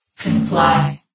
CitadelStationBot df15bbe0f0 [MIRROR] New & Fixed AI VOX Sound Files ( #6003 ) ...